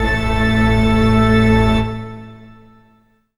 54_35_organ-A.wav